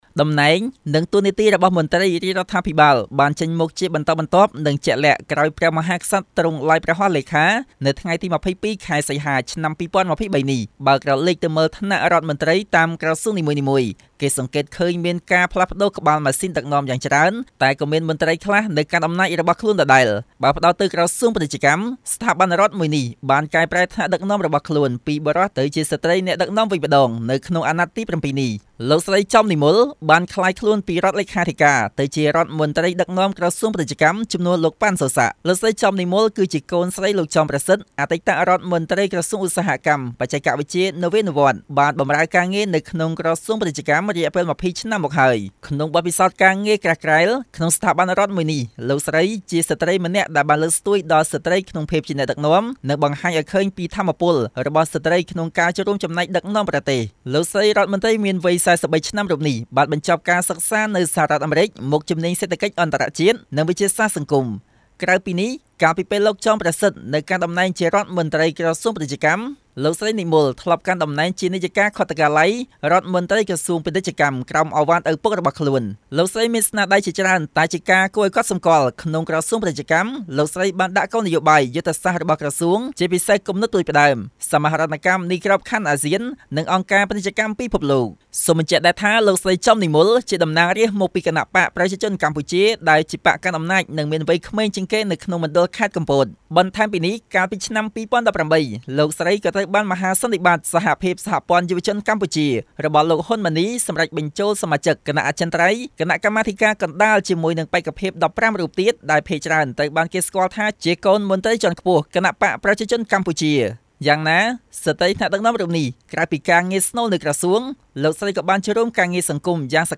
ជូននូវសេចក្ដីរាយការណ៍៖